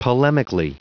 Prononciation du mot polemically en anglais (fichier audio)
Prononciation du mot : polemically